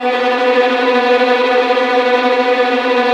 Index of /90_sSampleCDs/Roland L-CD702/VOL-1/STR_Vlns Tremelo/STR_Vls Tremolo